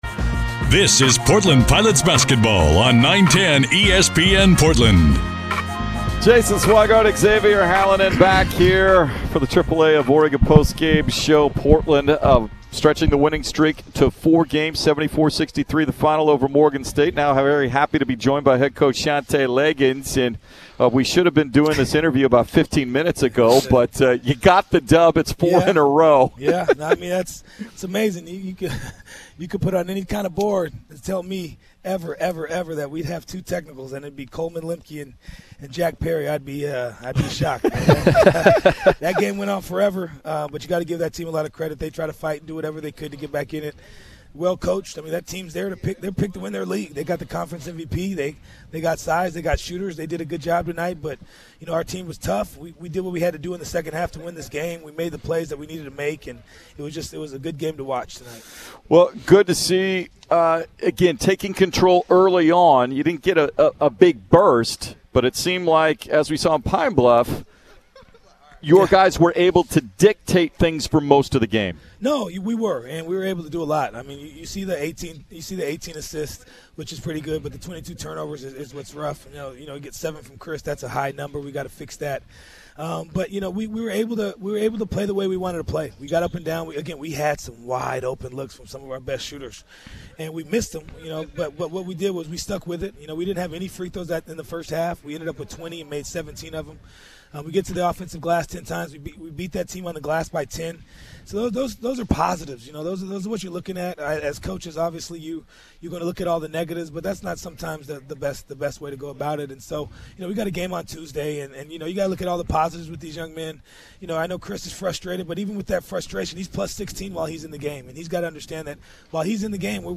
Morgan State Post-Game Interviews
Postgame_Interviews.mp3